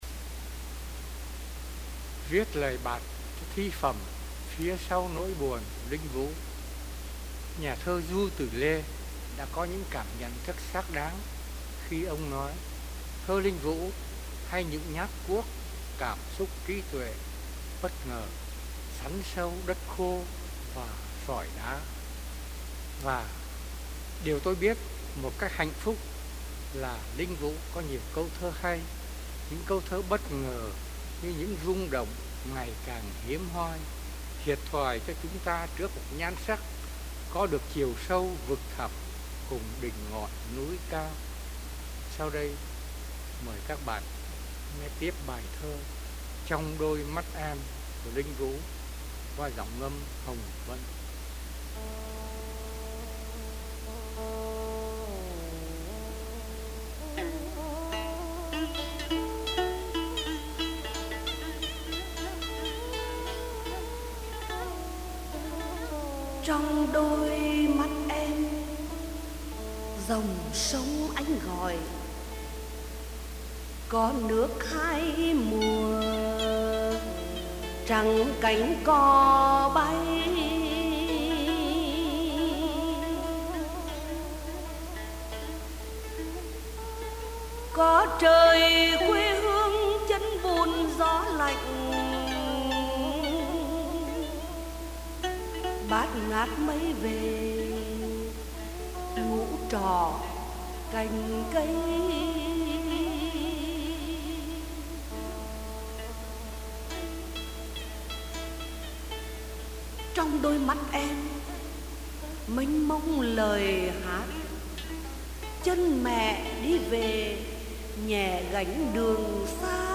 Phần 2: Ngâm thơ Thi tập phía sau nỗi buồn